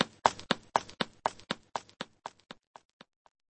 jiaobusheng.mp3